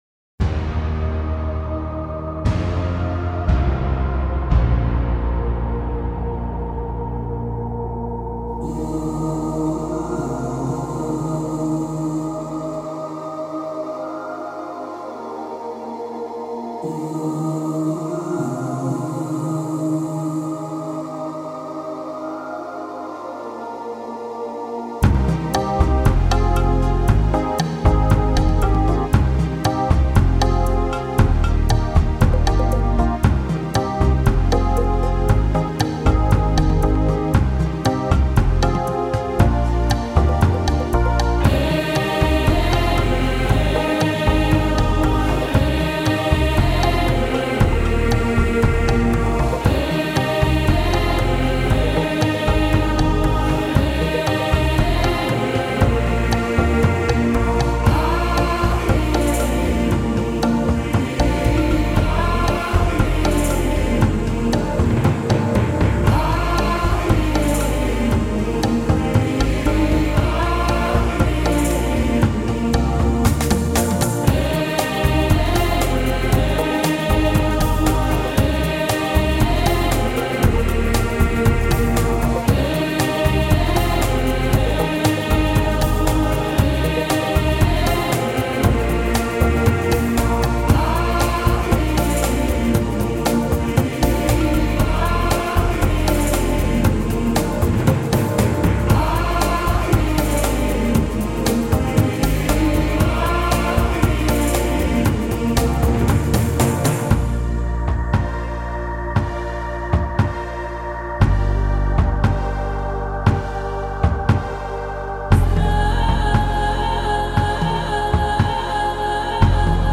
开篇，4下浑厚的鼓声及紧随而来的一阵飘渺的和声就立即牢牢地吸引住了听众的耳朵。